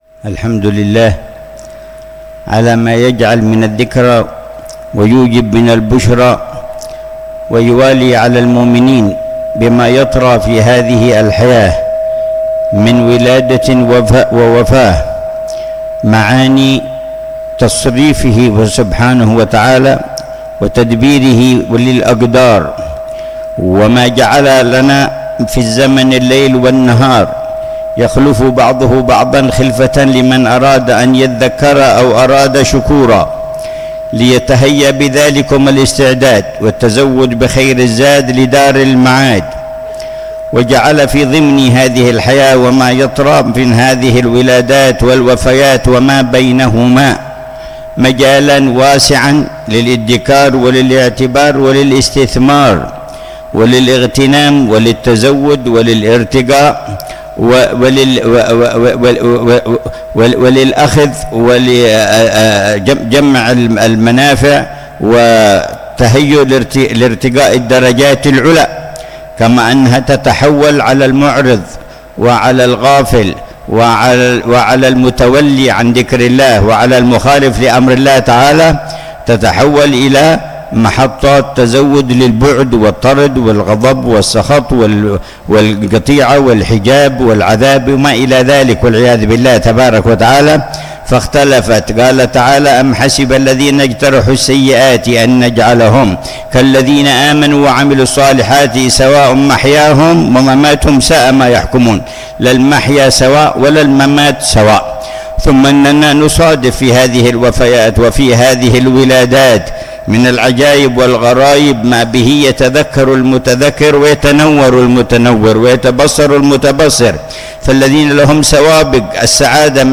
كلمة
في مجلس عزاء المنتقلة إلى رحمة الله